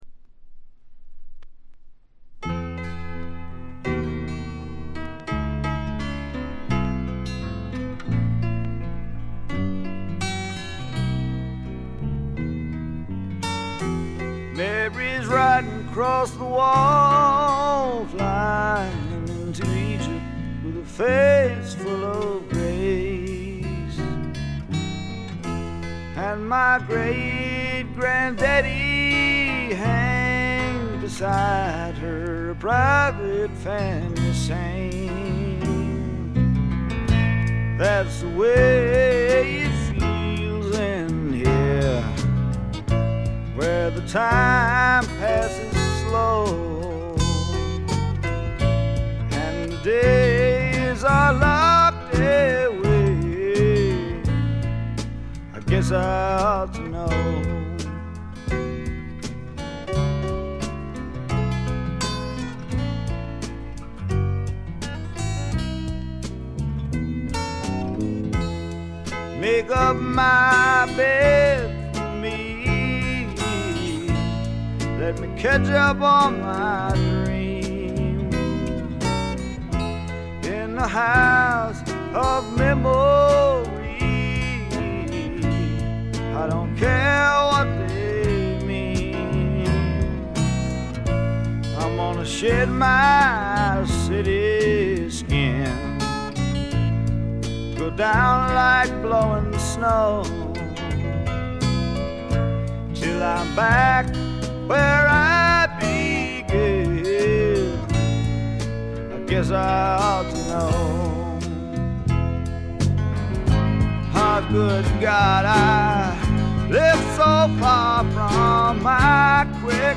軽微なバックグラウンドノイズ、チリプチ程度。
聴くものの心をわしづかみにするような渋みのある深いヴォーカルは一度聴いたら忘れられません。
試聴曲は現品からの取り込み音源です。